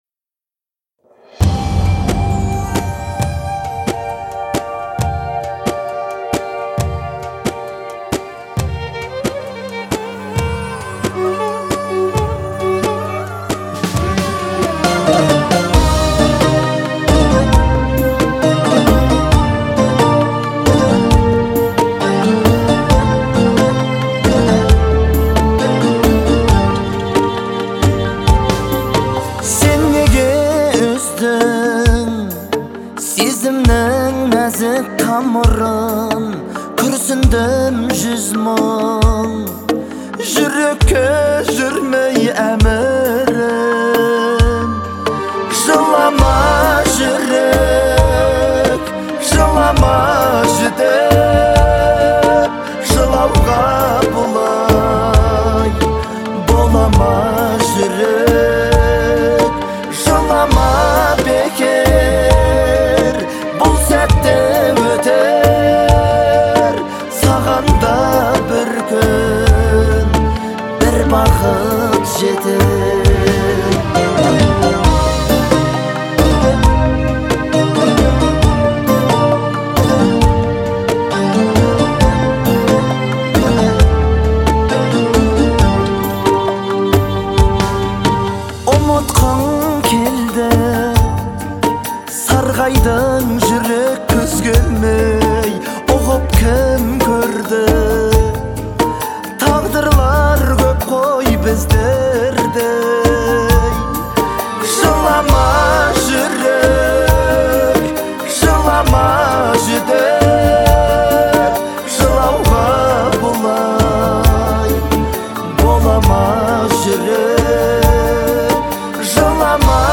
это трогательная композиция в жанре поп